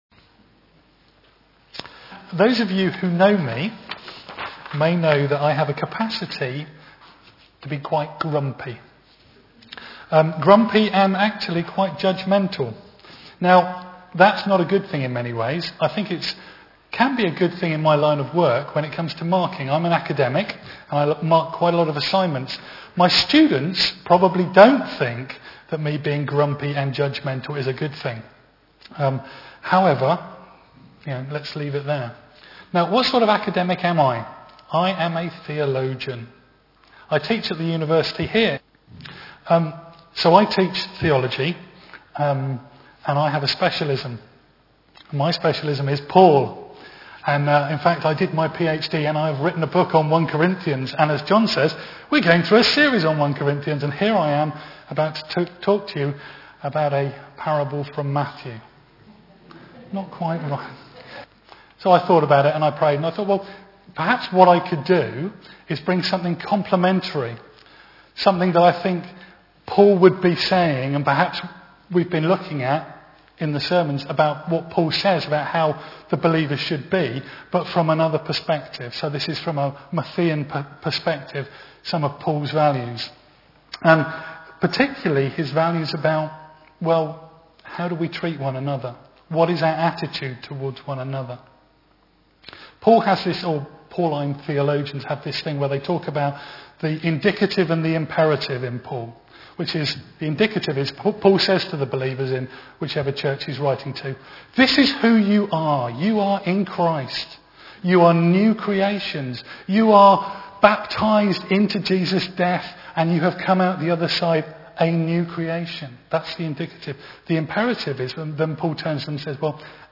Series: One Off Sermons